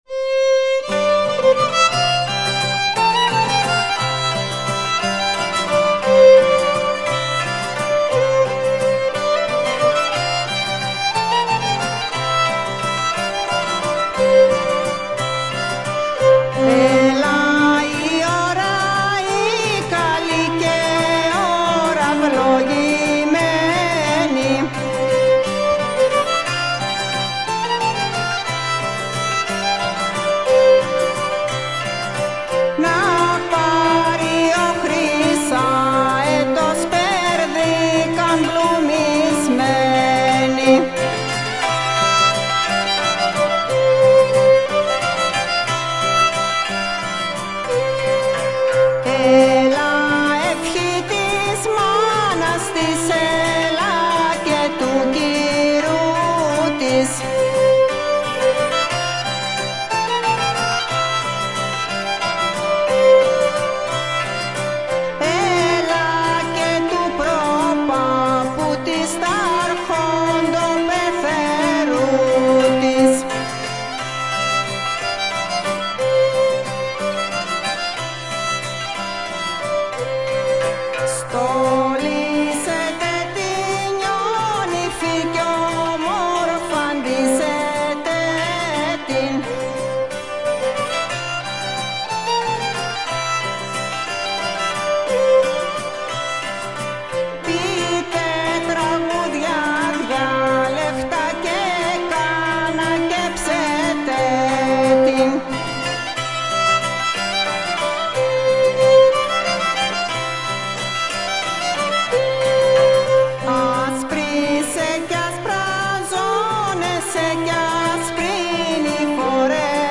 ΤΡΑΓΟΥΔΙΑ ΚΑΙ ΣΚΟΠΟΙ ΑΠΟ ΤΑ ΔΩΔΕΚΑΝΗΣΑ